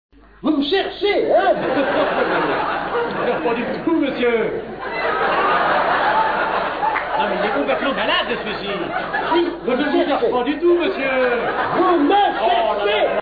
En exclusivité, des extraits en mp3 du spectacle de